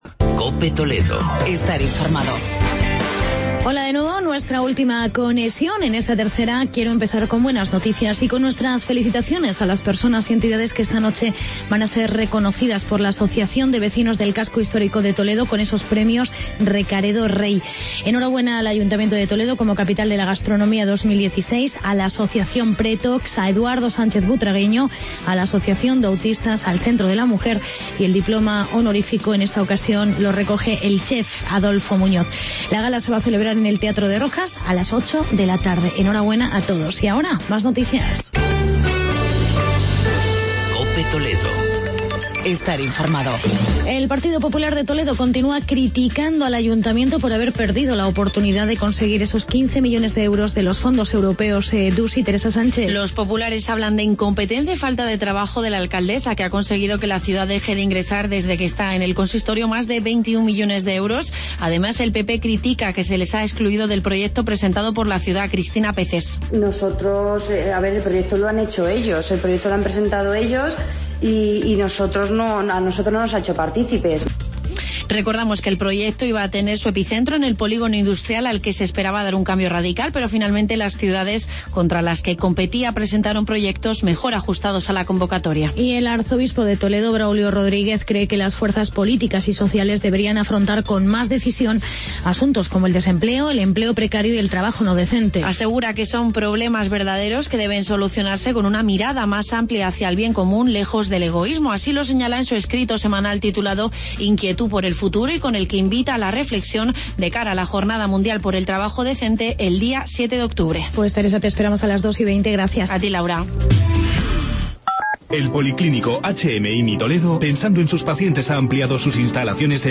Actualidad y entrevista con la portavoz del Ayuntamiento de Talavera, María Rodríguez.